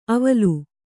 ♪ avalu